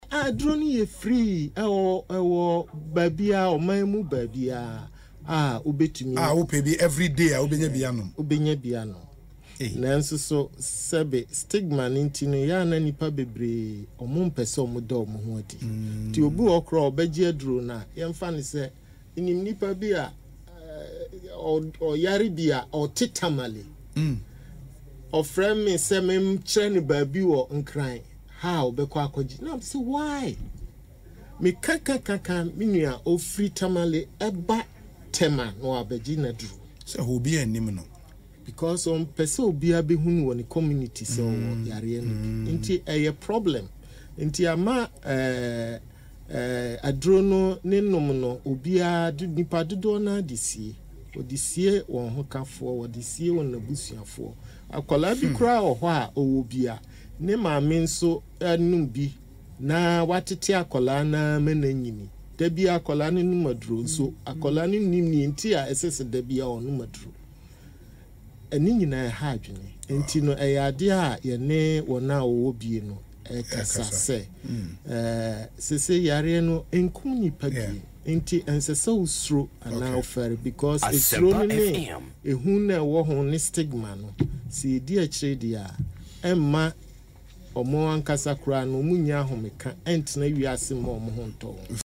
In an interview on Asempa FM’s Ekosii Sen, Dr. Atuahene explained that this reluctance to seek treatment is largely due to the stigma associated with HIV/AIDS.